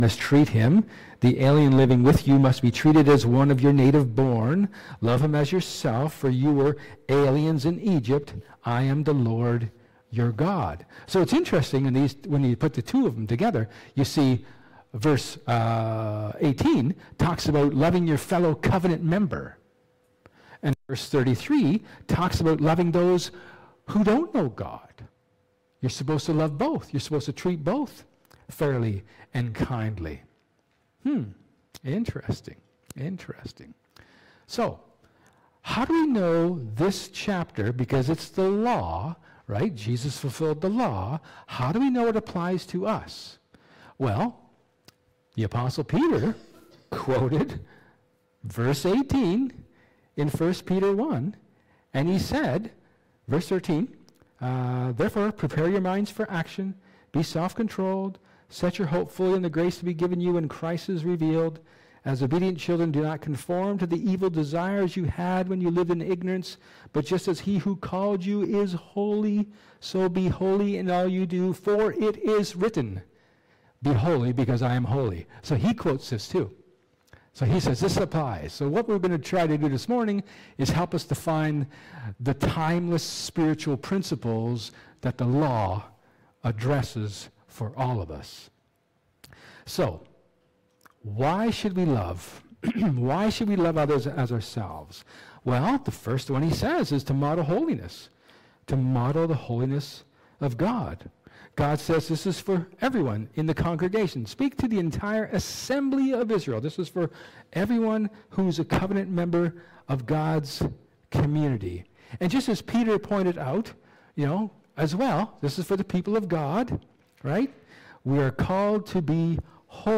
Leviticus 19 Service Type: Sermon Jesus quotes Lev. 19 as part of the greatest commandment.